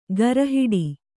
♪ gara hiḍi